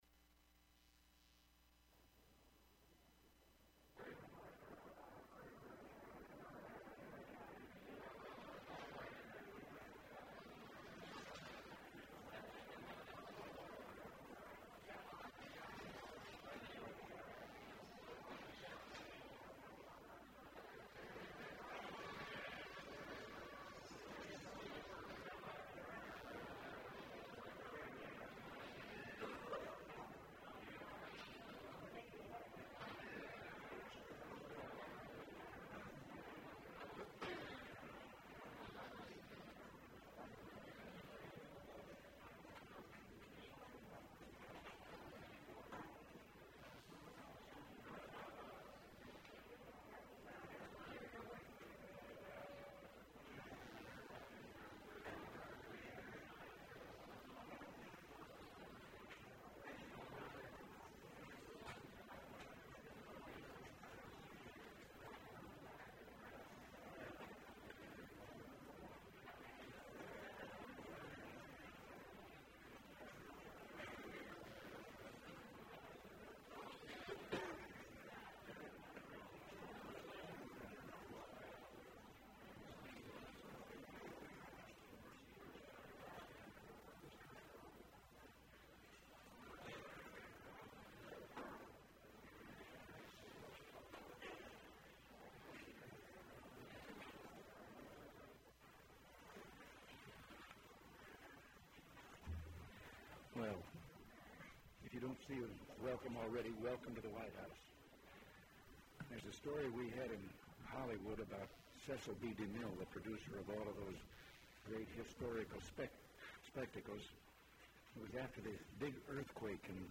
President Reagan’s remarks at a luncheon for Radio and TV journalists in state dining room
MP3 Audio file Tape Number WHMEDIA AUD-18 Side 1 Date 06/08/1988 Time Counter Reading 31:15 Personal Reference Ronald Reagan Collection Reference WHMEDIA Geographic Reference Washington, DC